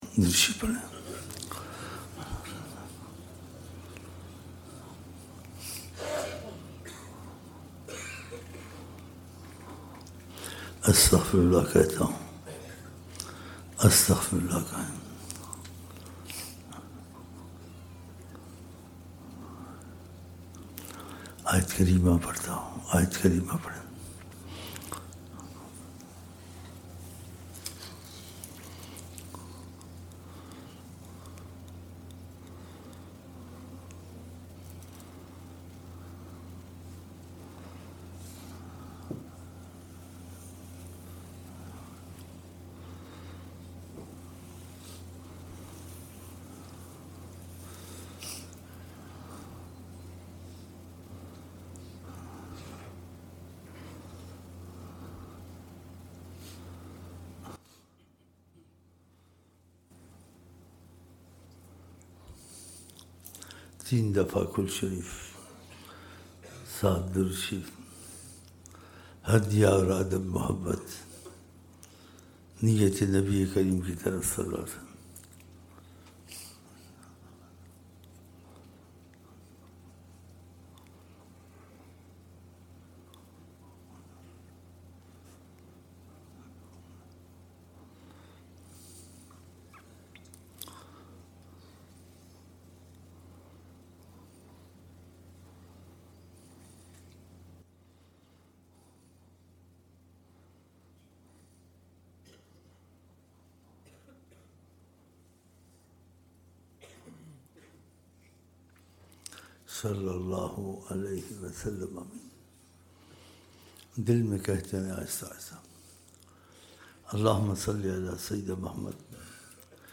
5 January 2007 Friday Esha Mehfil (14 Dhu al-Hijjah 1427 AH)